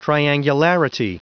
Prononciation du mot triangularity en anglais (fichier audio)
Prononciation du mot : triangularity